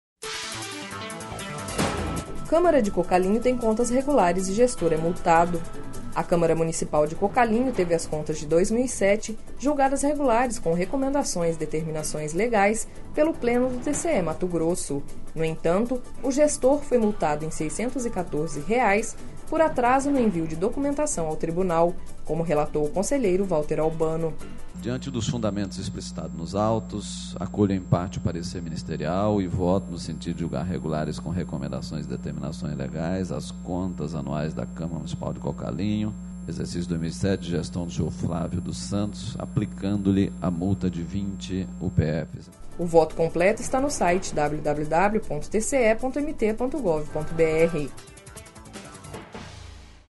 Sonora: Valter Albano - conselheiro do TCE-MT